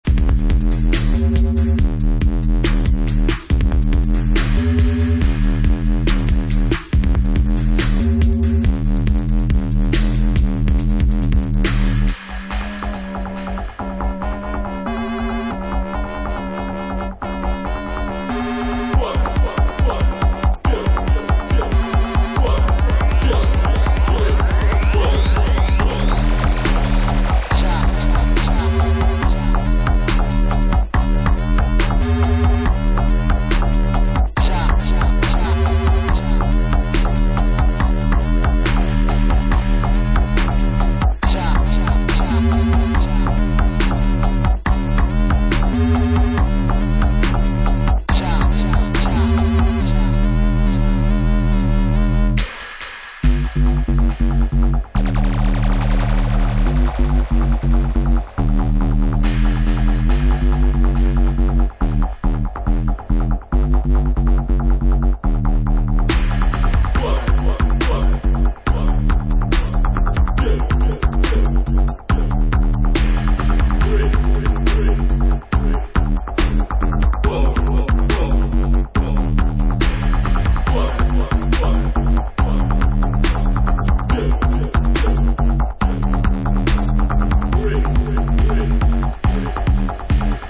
Dubstep remixes of some true classics.